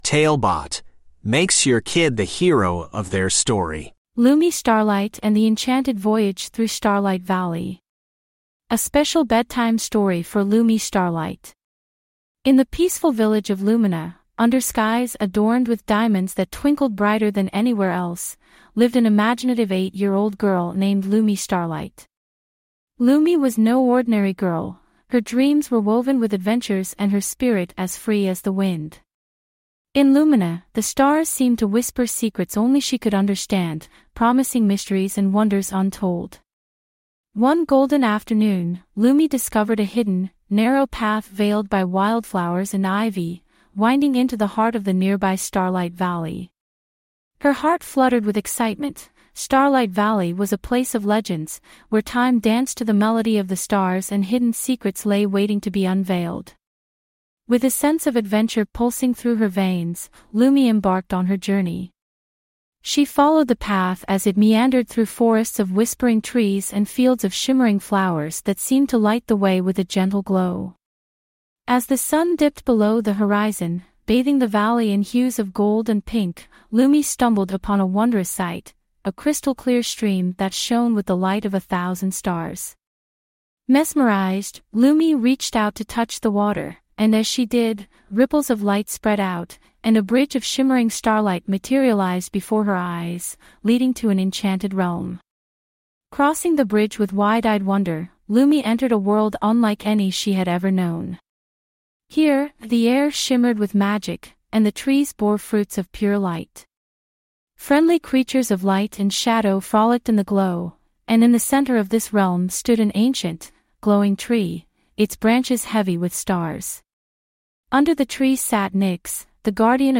TaleBot Bedtime Stories
TaleBot AI Storyteller